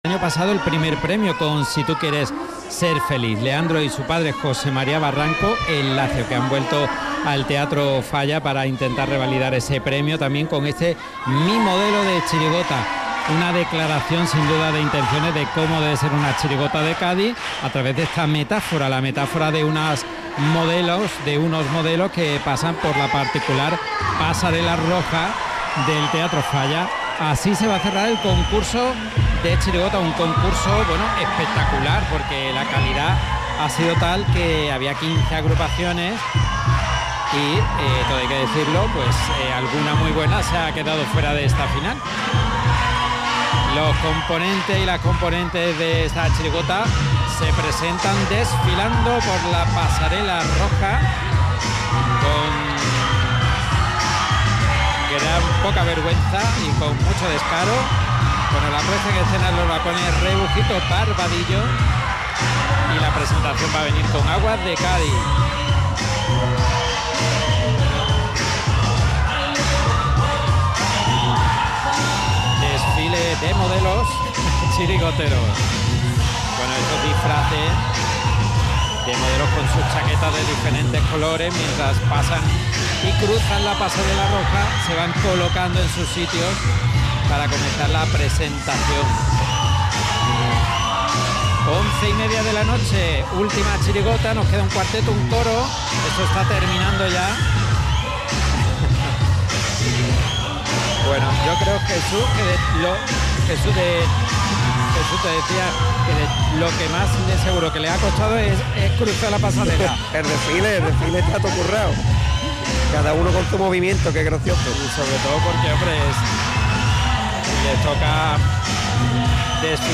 Chirigota Infantil - Mi modelo... de chirigota Final